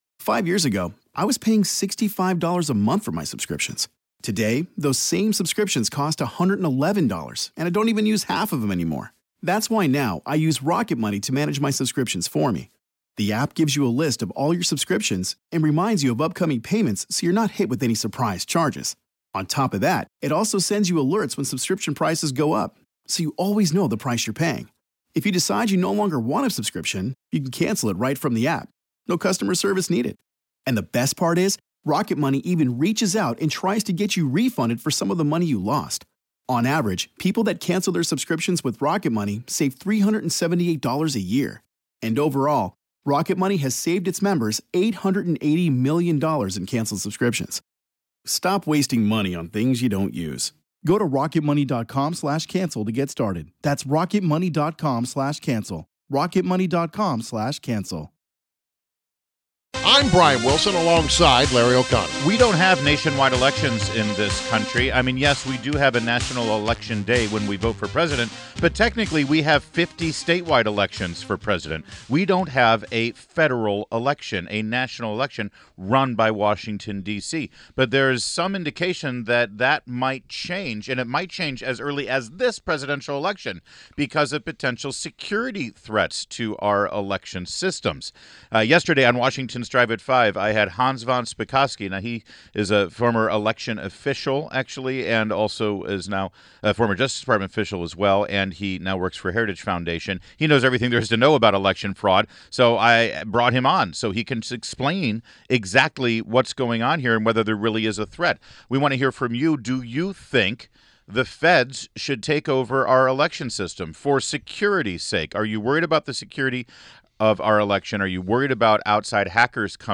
WMAL Interview - HANS VON SPAKOVSKY - 08.31.16